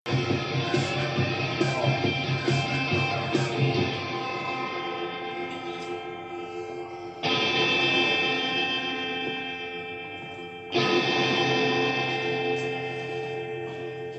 Sicher mit Reverb (klar).